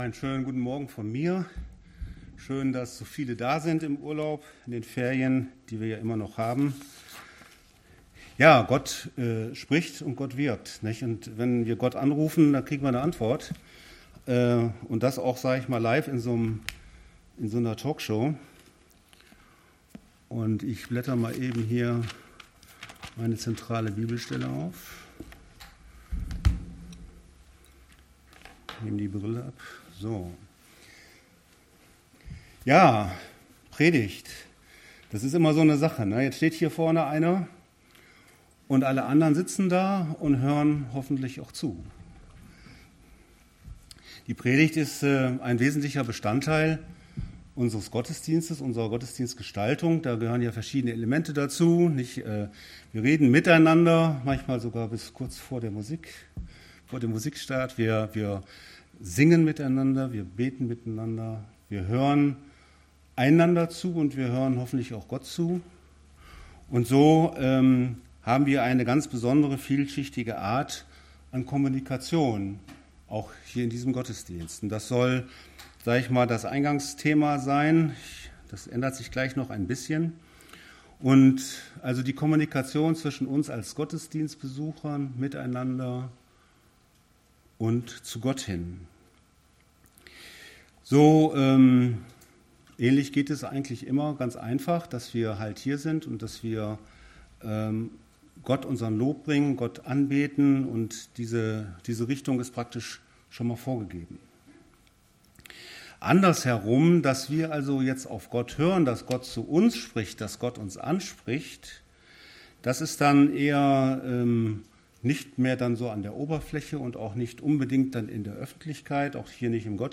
Evangelisch-Freikirchliche Gemeinde Borken - Predigten anhören